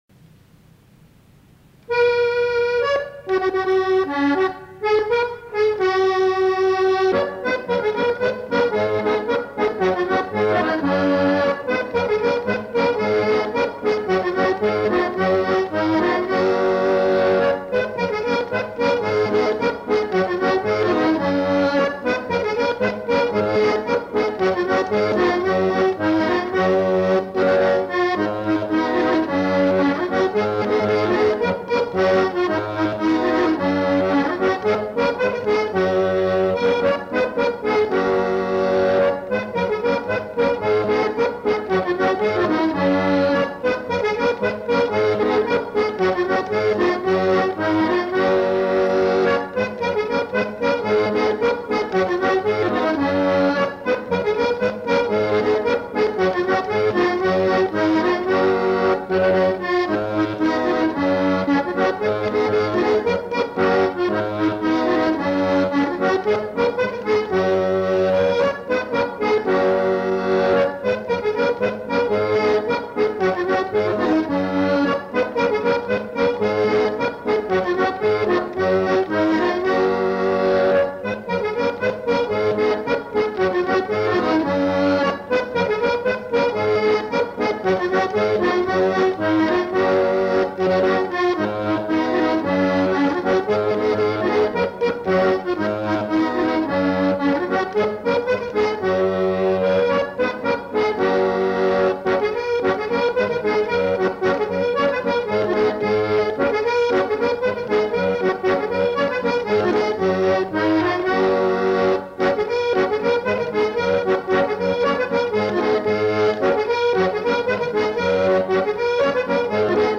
Instrumental. Accordéon diatonique
Lieu : Monclar d'Agenais
Genre : morceau instrumental
Instrument de musique : accordéon diatonique